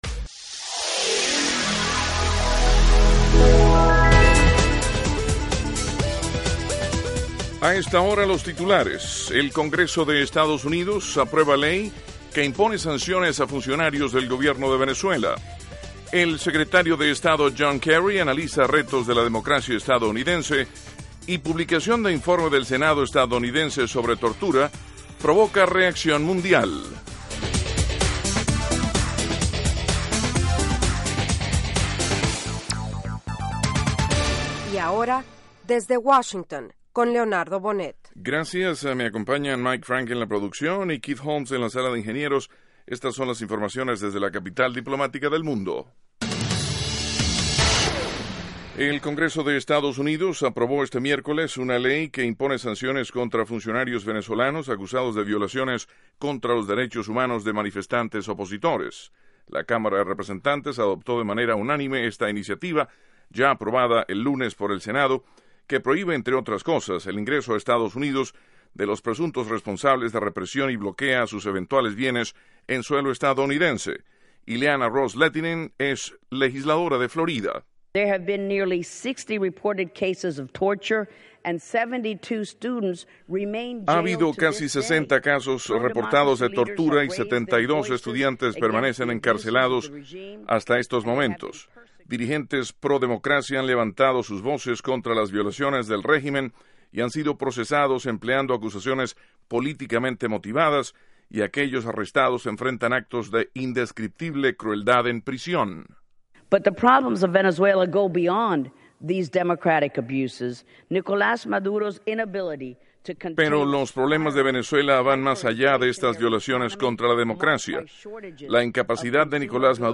Diez minutos de noticias sobre los acontecimientos de Estados Unidos y el mundo.